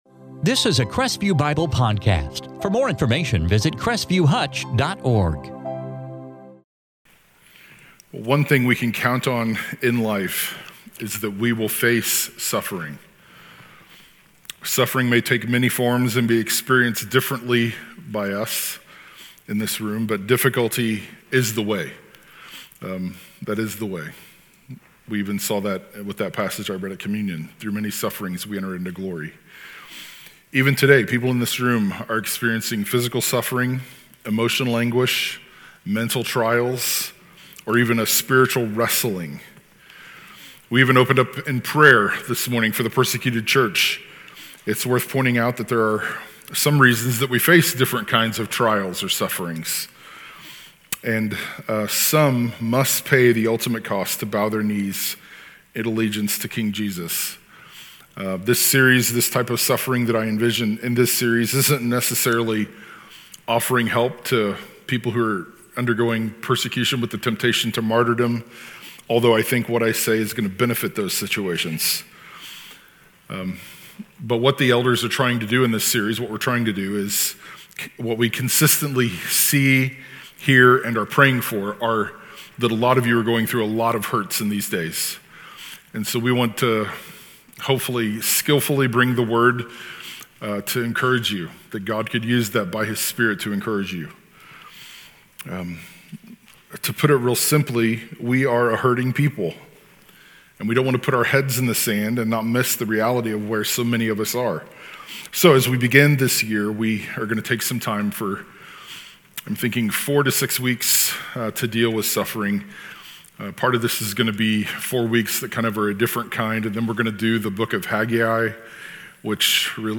I envision this opening sermon as a place to dig into the heaviness of suffering and its expressi